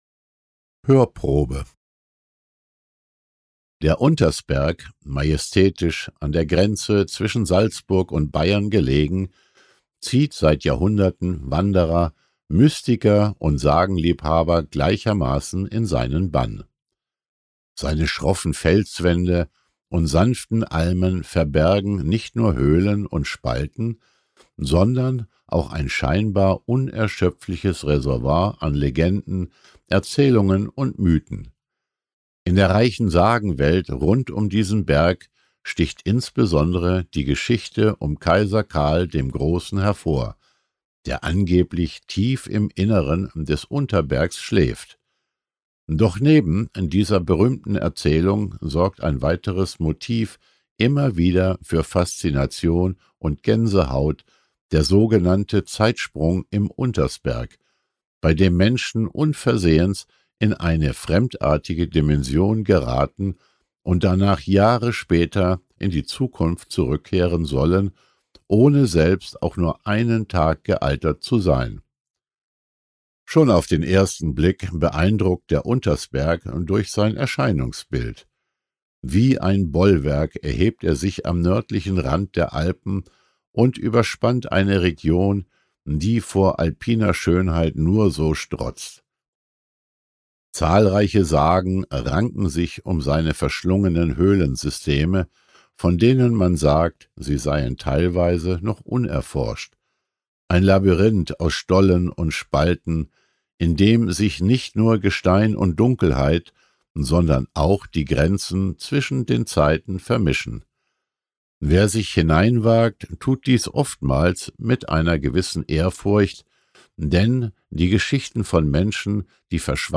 Dieses Hörbuch versammelt die bekanntesten und faszinierendsten Erzählungen aus Österreich: vom sagenumwobenen Donaudrachen über düstere Geisterschlösser in den Alpen bis hin zu verzauberten Gestalten, die seit Jahrhunderten in Tälern, Wäldern und Bergregionen ihr Unwesen treiben.
Mythen-Sagen-und-Legenden-Hoerprobe.mp3